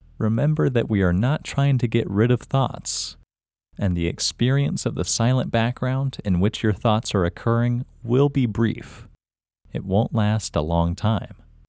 LOCATE OUT English Male 33